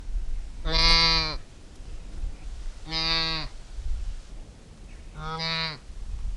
Звуки сайги
На этой странице собраны разнообразные звуки сайги – от голосовых сигналов до шумов, которые издают эти степные антилопы.